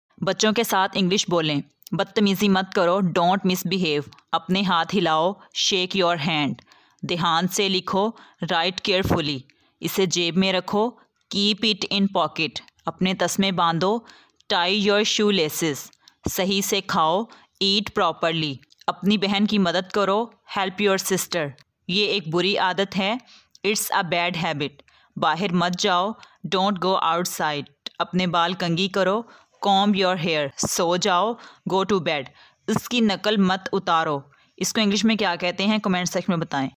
English accent